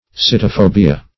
Search Result for " sitophobia" : The Collaborative International Dictionary of English v.0.48: Sitophobia \Si`to*pho"bi*a\, n. [NL., fr. Gr.